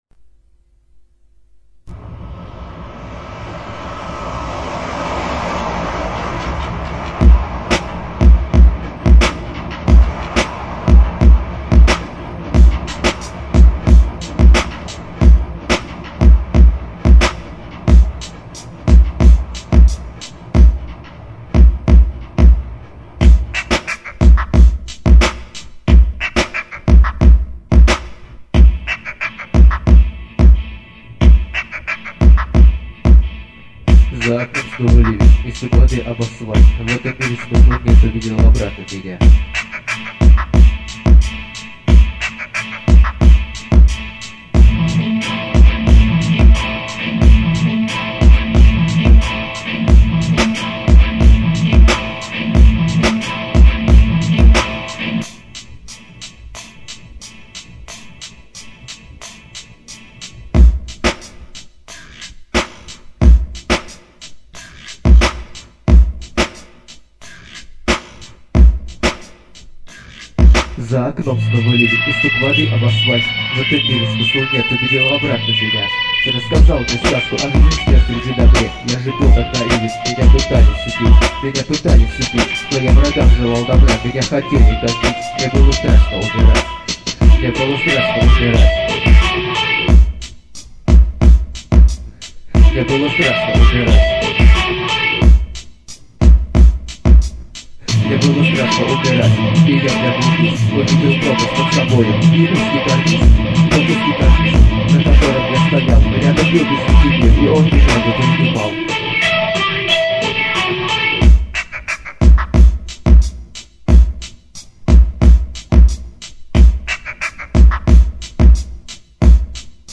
LIFE version remix